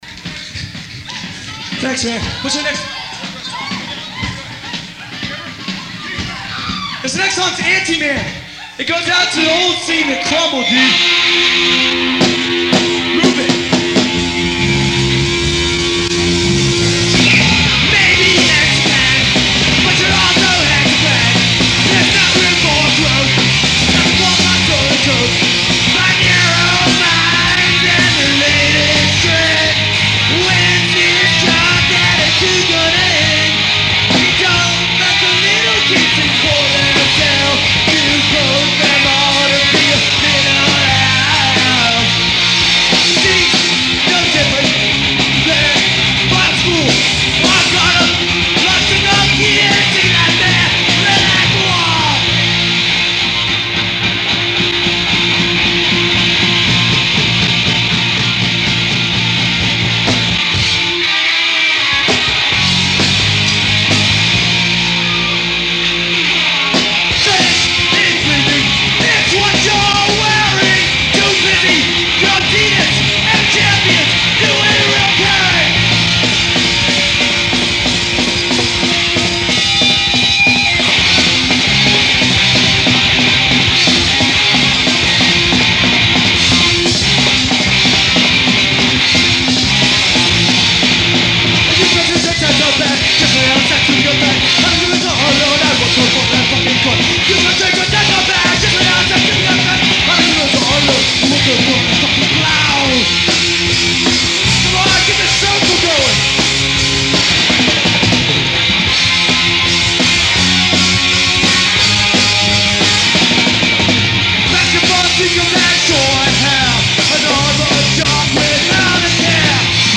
side a – Blackhole, Philadelphia 1-05-92